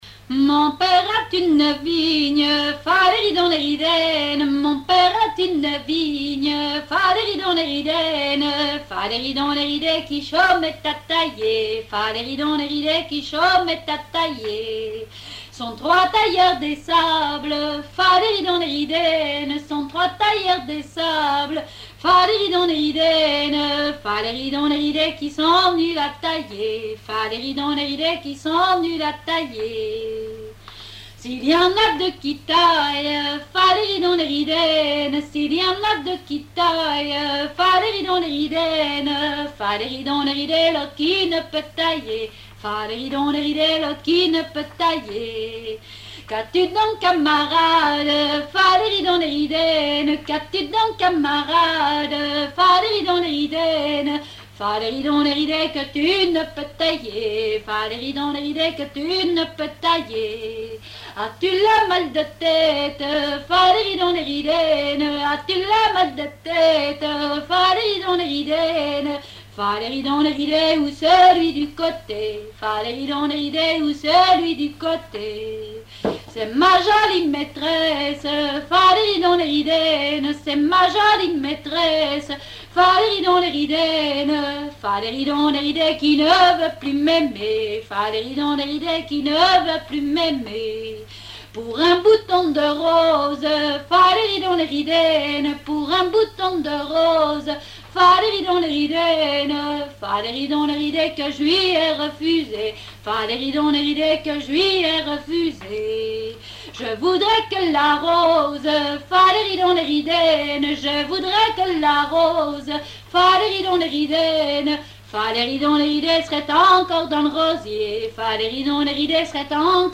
répertoire de chansons traditionnelles
Pièce musicale inédite